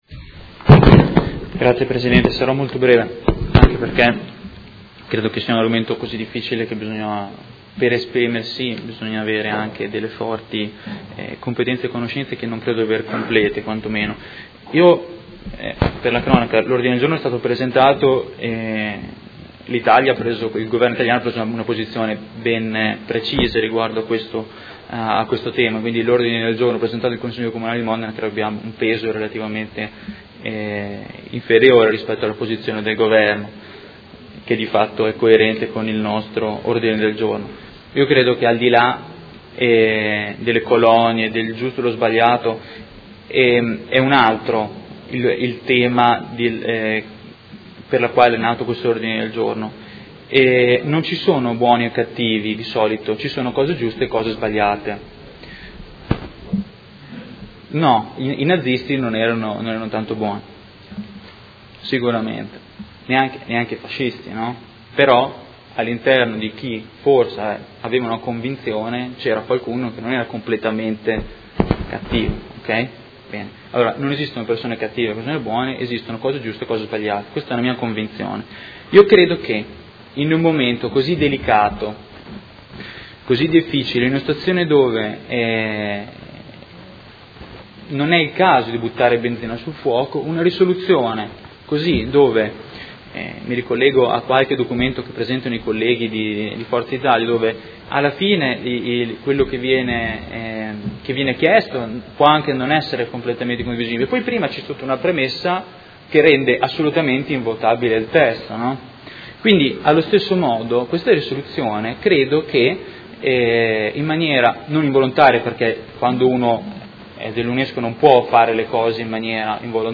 Diego Lenzini — Sito Audio Consiglio Comunale